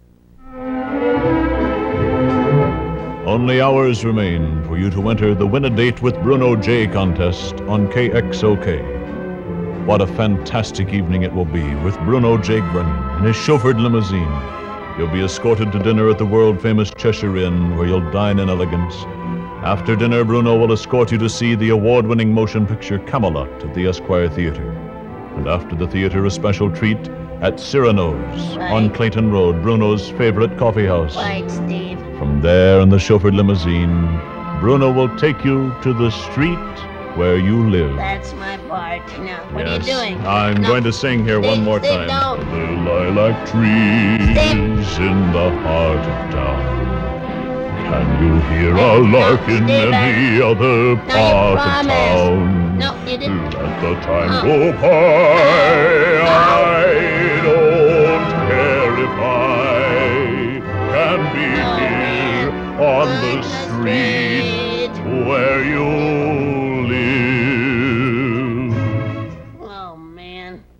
radio promo